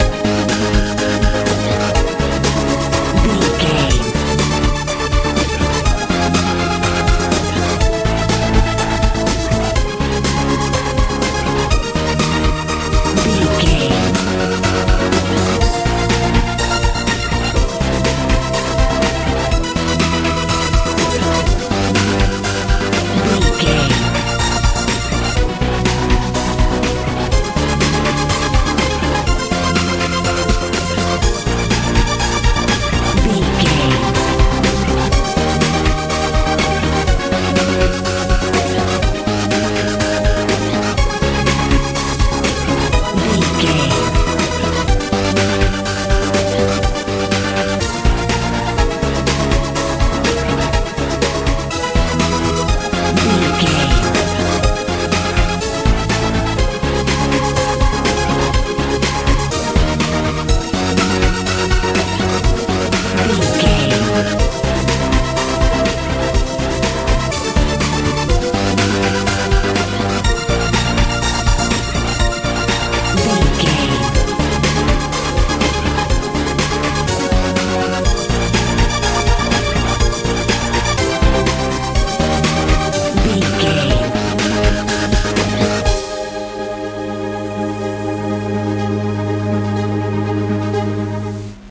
dance feel
Ionian/Major
A♭
energetic
motivational
piano
synthesiser
bass guitar
drums
80s
lively